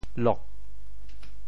调: 滴
国际音标 [lok]